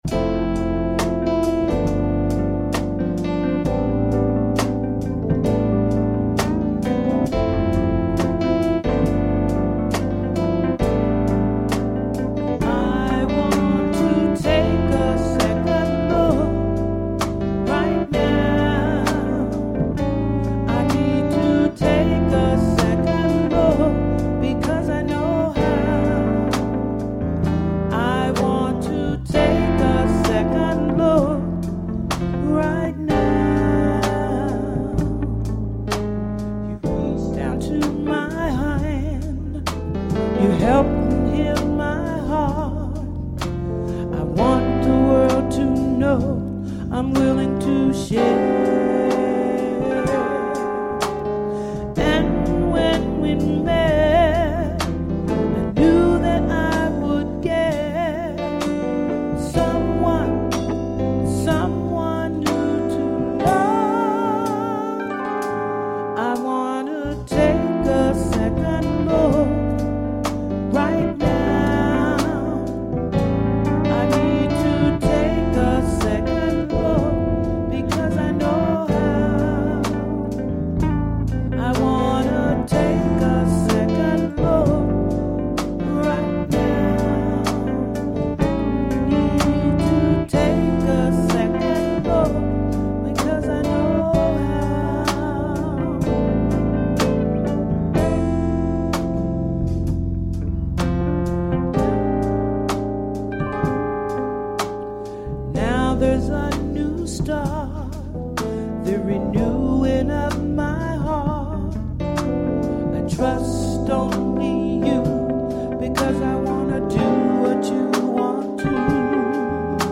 She was a stellar vocalist!